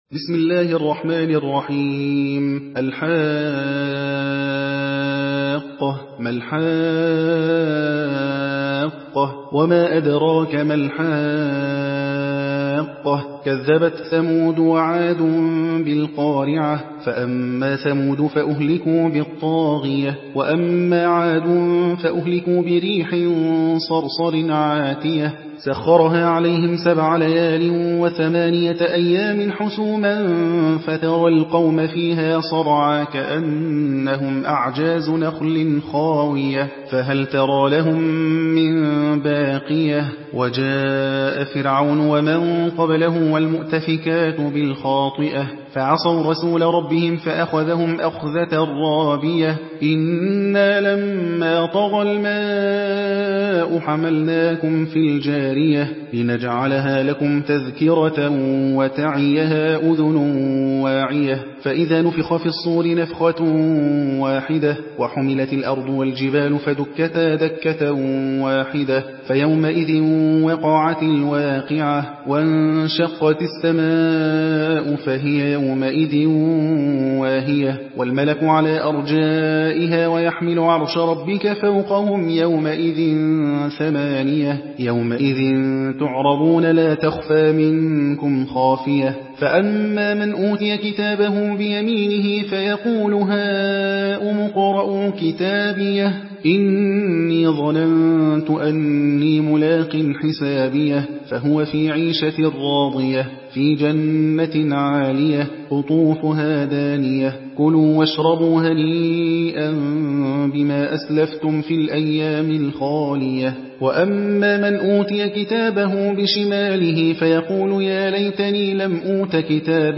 Hafs An Asim narration